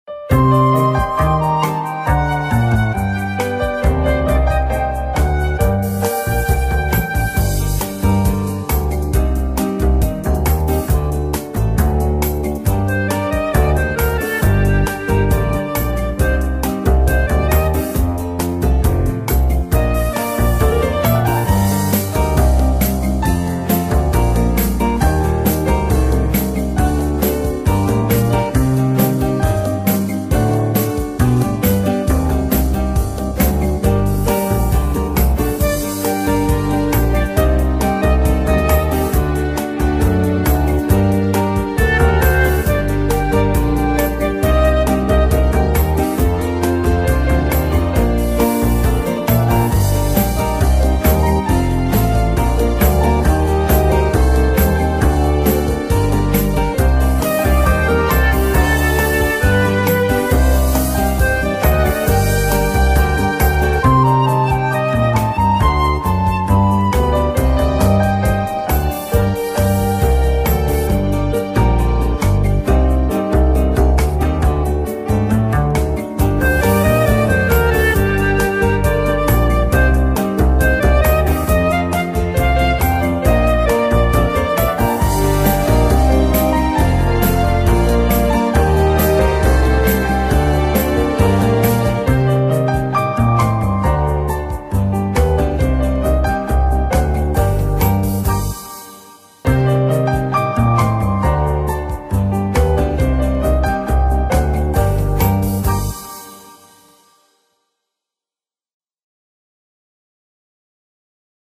Слушайте минус песни